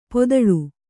♪ podaḷu